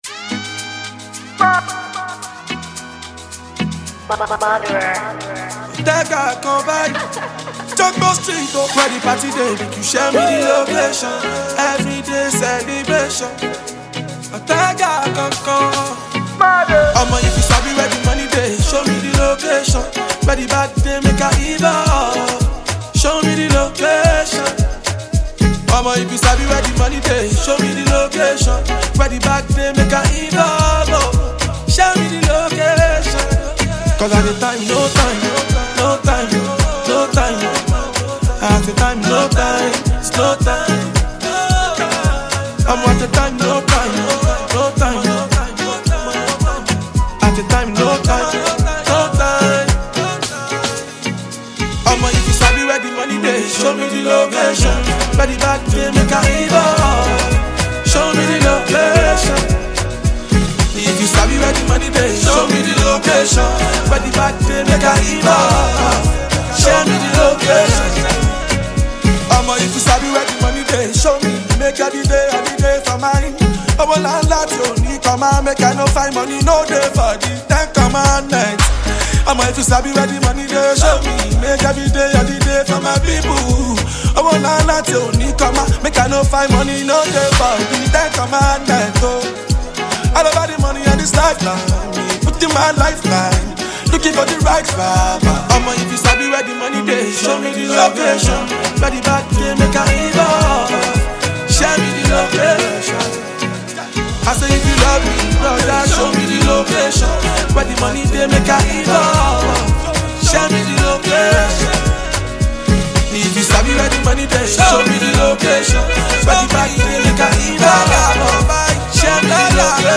Nigerian singer, rapper